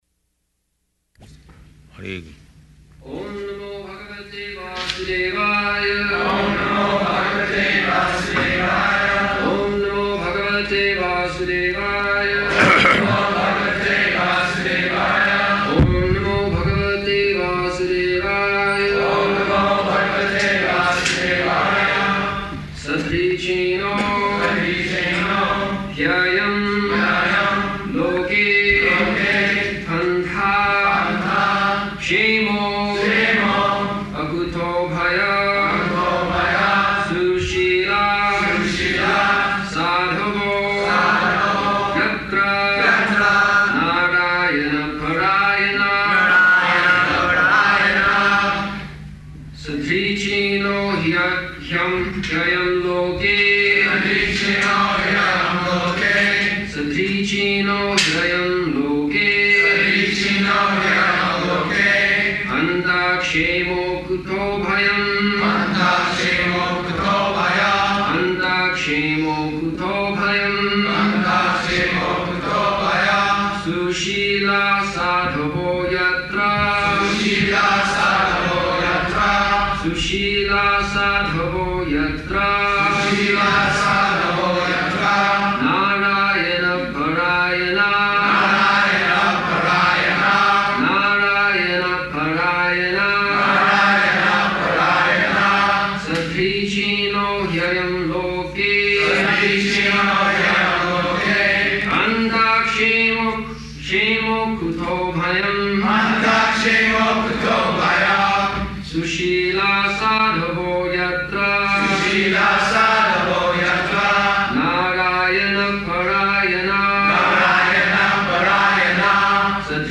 Śrīmad-Bhāgavatam 6.1.17 --:-- --:-- Type: Srimad-Bhagavatam Dated: June 30th 1975 Location: Denver Audio file: 750630SB.DEN.mp3 Prabhupāda: Hare Kṛṣṇa.
[devotees repeat] [leads chanting of verse, etc.]